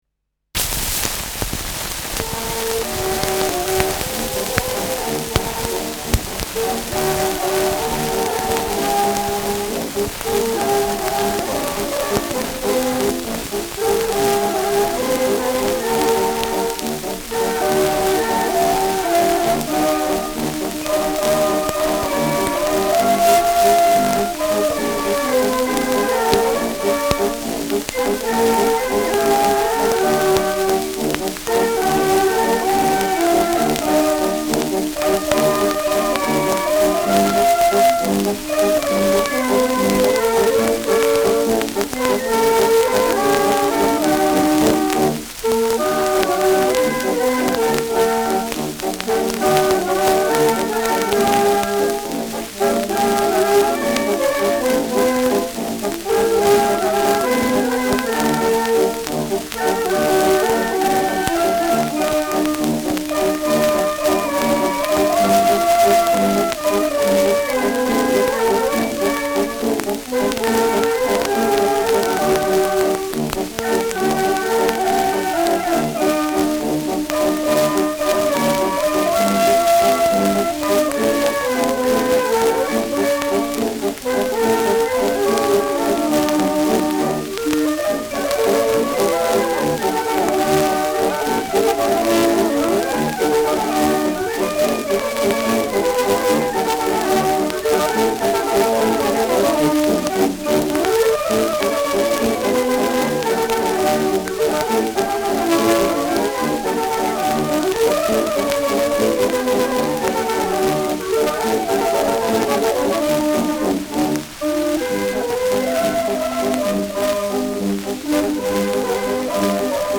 Schellackplatte
präsentes bis starkes Rauschen : präsentes Knistern : Knacken zu Beginn : abgespielt : gelegentliches „Schnarren“ : gelegentliches Knacken
Kapelle Greiß, Nürtingen (Interpretation)
Stadtkapelle Fürth (Interpretation)